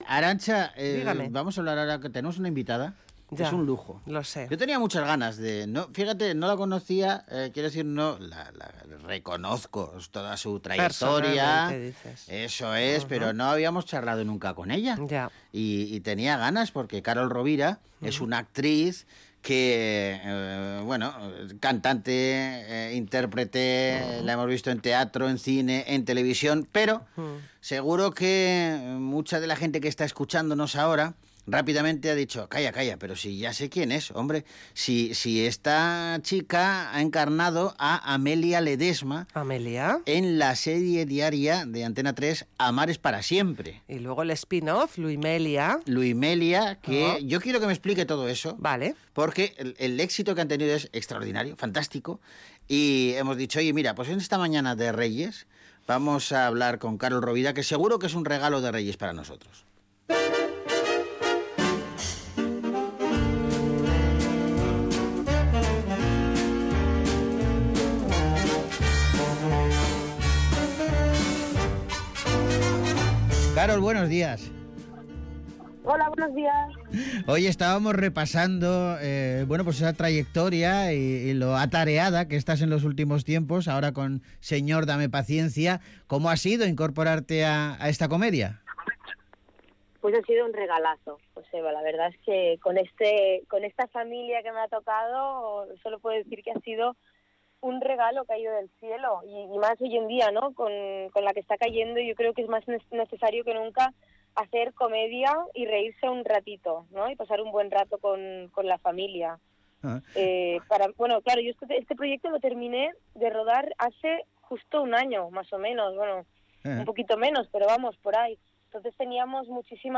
charla de su trayectoria y proyectos en 'Bogart Baila con Lobos'